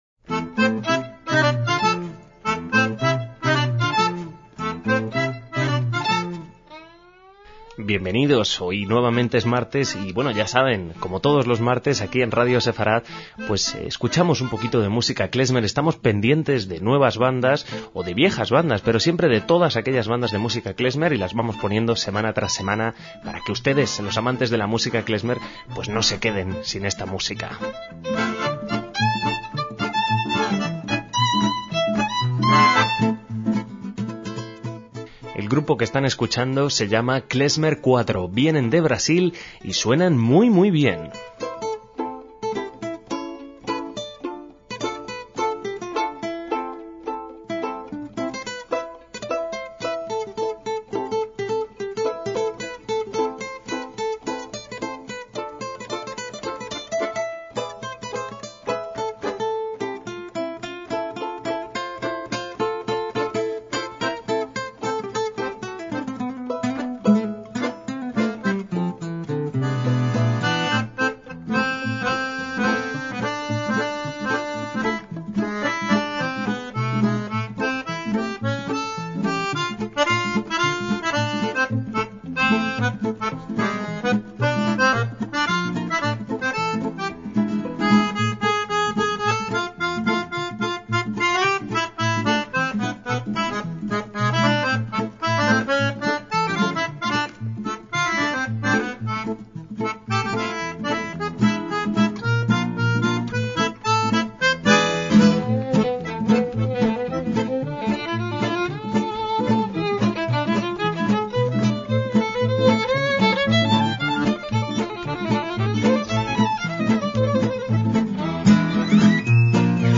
MÚSICA KLEZMER
violín, trombón y flauta
acordeón y teclado